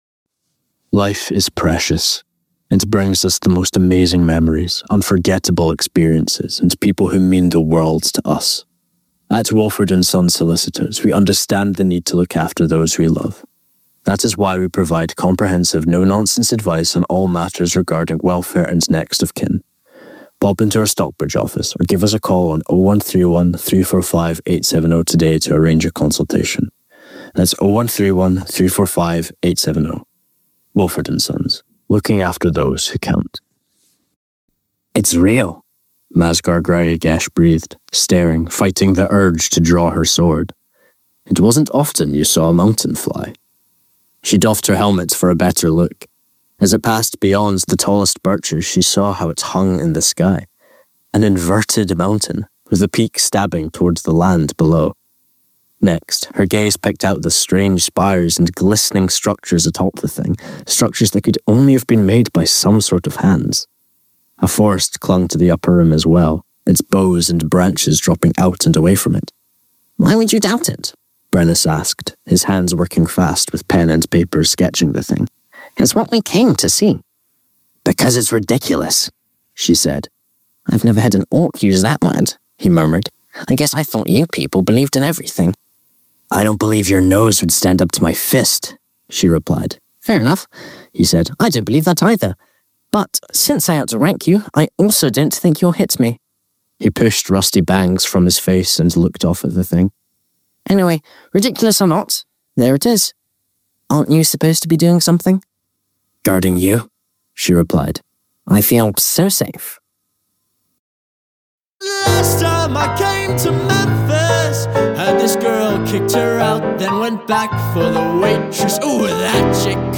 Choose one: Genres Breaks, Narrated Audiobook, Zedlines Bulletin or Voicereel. Voicereel